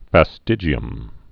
(fă-stĭjē-əm)